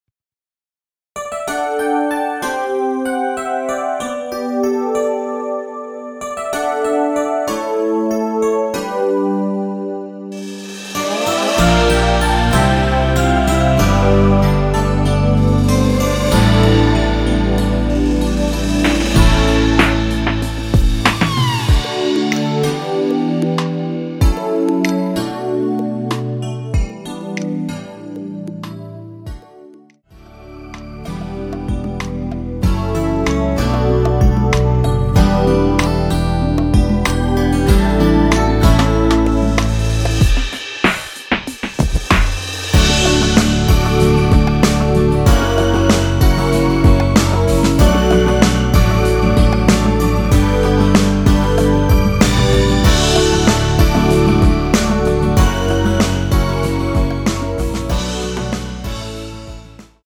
원키에서(-2)내린 MR입니다.
D
앞부분30초, 뒷부분30초씩 편집해서 올려 드리고 있습니다.
중간에 음이 끈어지고 다시 나오는 이유는